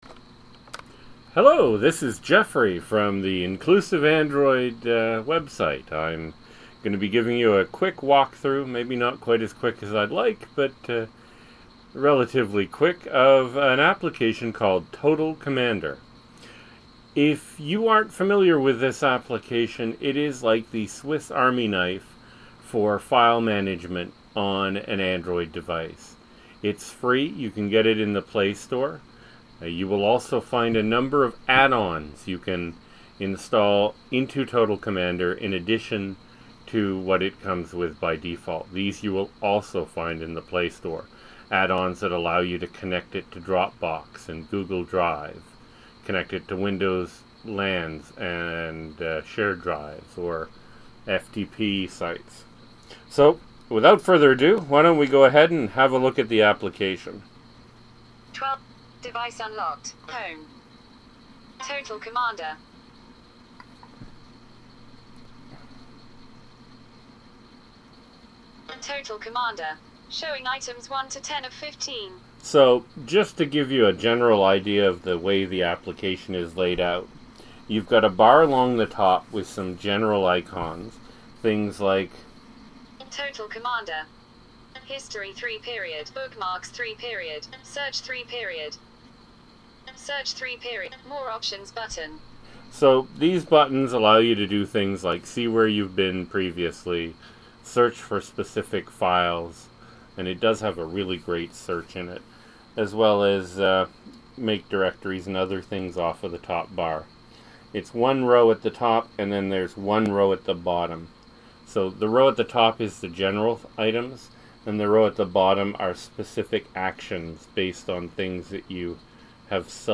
An audio walk through of the fantastic free app called Total Commander that is the swiss army knife of file management on an android device with Talkback running.